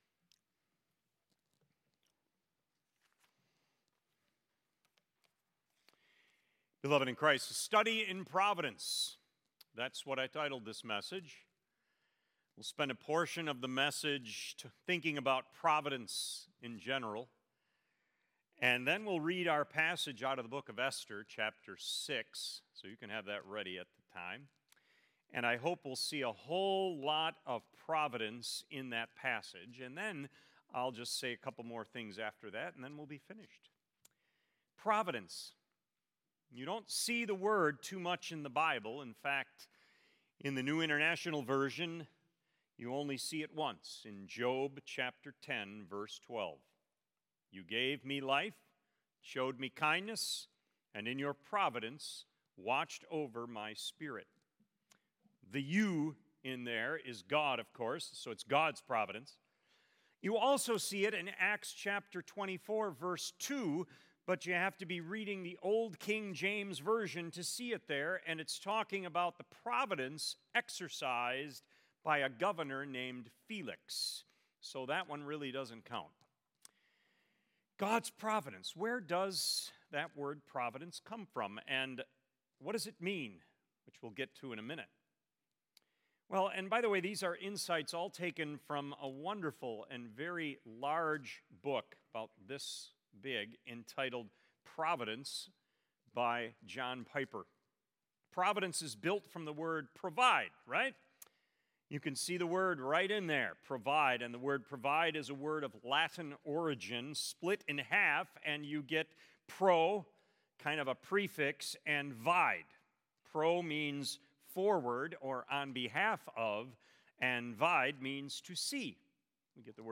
Sermons | Faith Community Christian Reformed Church